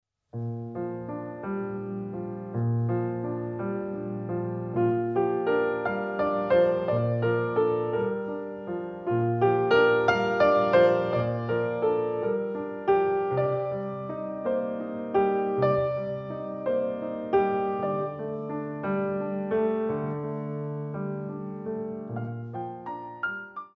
Foot Strengthening at the Barre